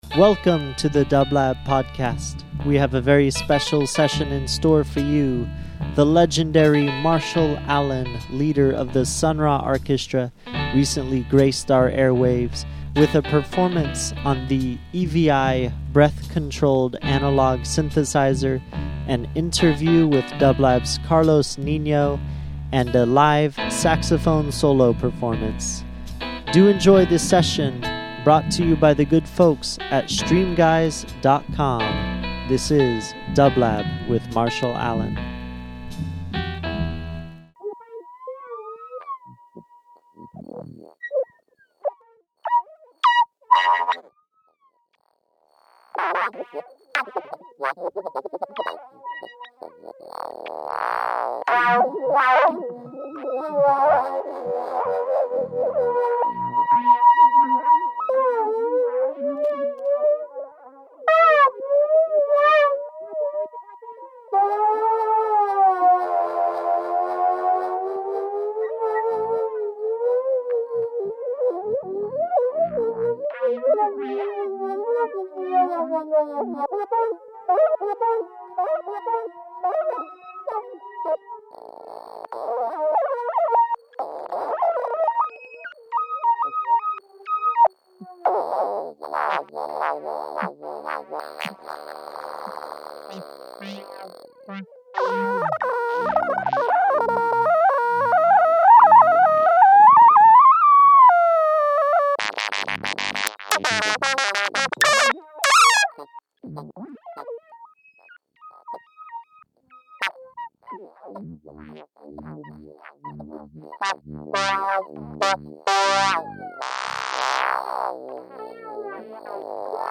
E.V.I. breath controlled synthesizer
live saxophone solos